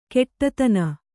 ♪ keṭṭatana